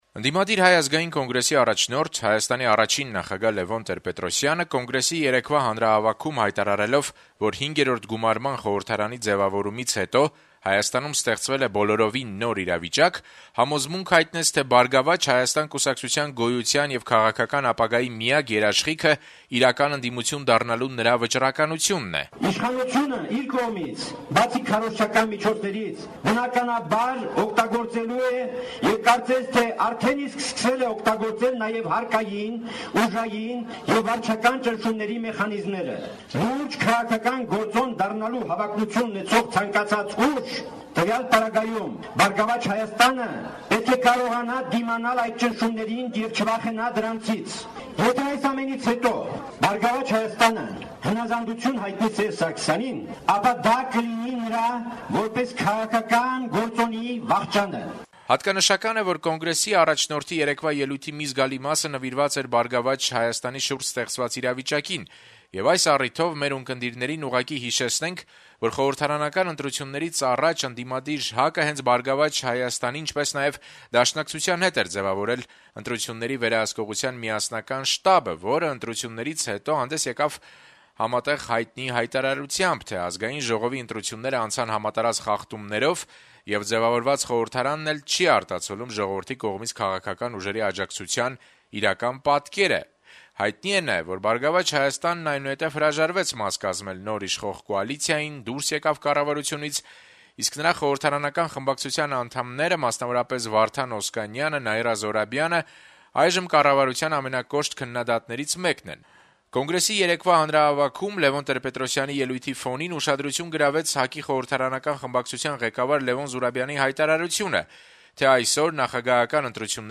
ԱԺ-ում ներկայացված ոչ կոալիցոն ուժերի ներկայացուցիչները «Ազատություն» ռադիոկայանի հետ զրույցներում մեկնաբանում են ՀԱԿ-ի խորհրդարանական խմբակցության ղեկավարի` երեկվա հանրահավաքում արած հայտարարությունը: